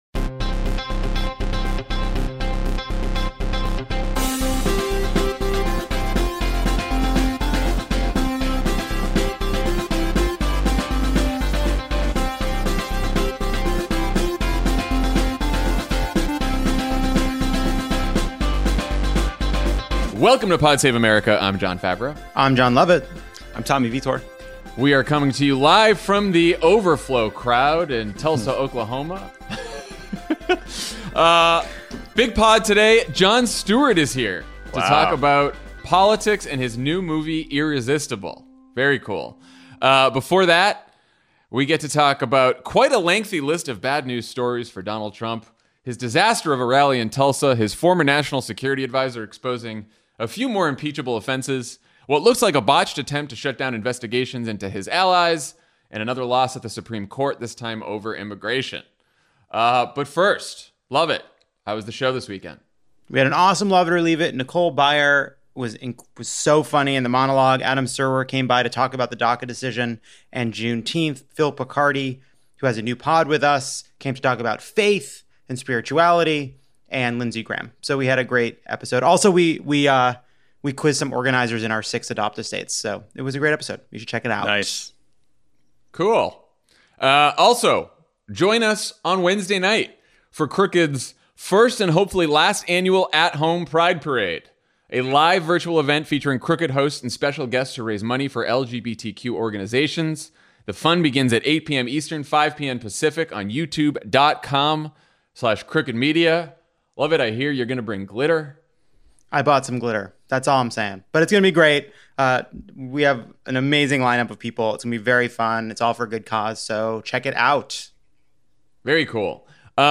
Trump tanks in Tulsa with a small crowd and rambling speech, fires a U.S. Attorney who was investigating his allies, loses a legal battle to stop John Bolton’s book from being published, and loses the Supreme Court decision over DACA. Then Jon Stewart talks to Jon Favreau about money in politics, the media, and his new movie, Irresistible.